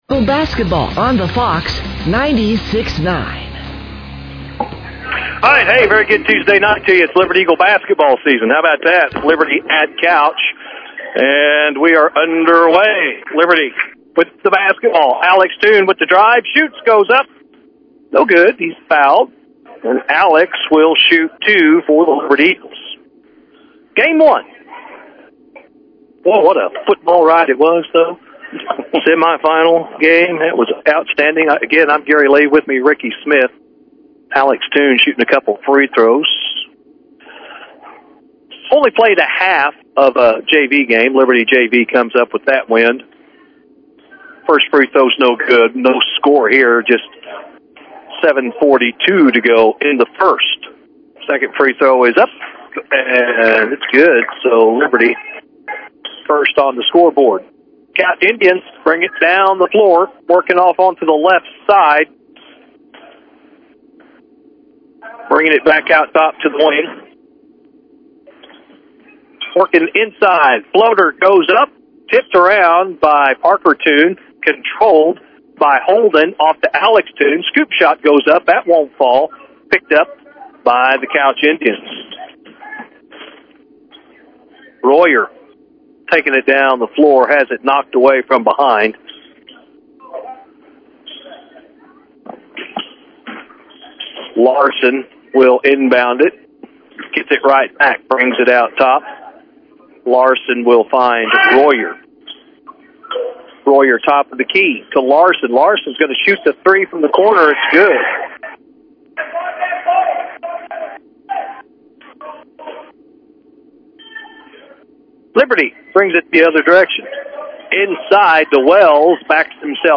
The Liberty Eagles Boys Basketball team was on the road for their first Basketball season to Couch.